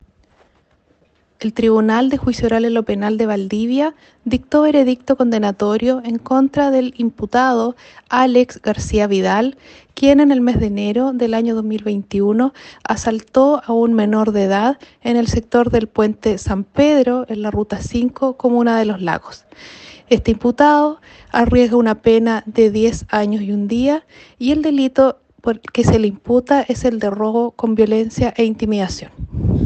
Fiscal Claudia Baeza